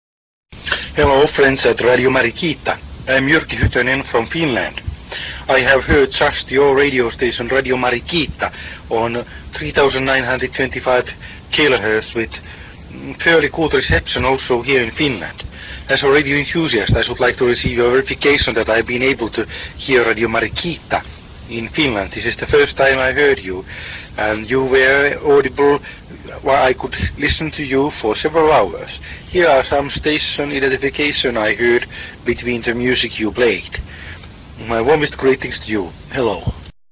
with Tape inclused of reception report from